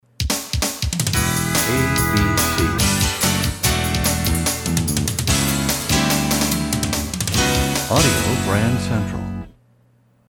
MCM Category: Radio Jingles
Genre: Jingles.